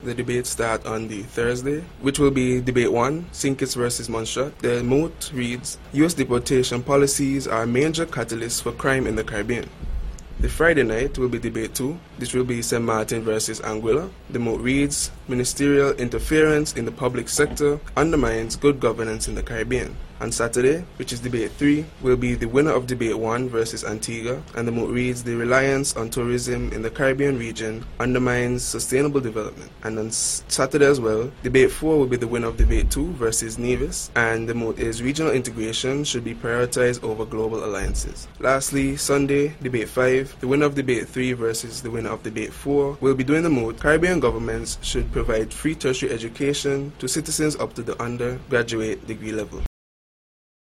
DEBATE-TOPICS-MARCH-9TH-2026.mp3